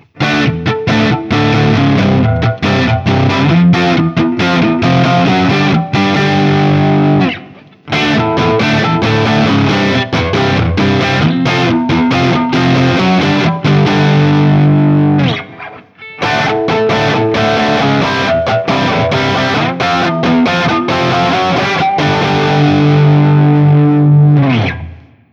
This is an aggressive sounding guitar that’s a bit dark for my tastes, a fact that I attribute to the Guild XR7 pickups and the mahogany body.
JCM-800
A Barre Chords
As usual, for these recordings I used my normal Axe-FX II XL+ setup through the QSC K12 speaker recorded direct into my Macbook Pro using Audacity.
For each recording I cycle through the neck pickup, both pickups, and finally the bridge pickup.
Guild-X79-JCM800-A.wav